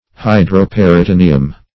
Search Result for " hydroperitoneum" : The Collaborative International Dictionary of English v.0.48: Hydroperitoneum \Hy`dro*per`i*to*ne"um\, n. [NL.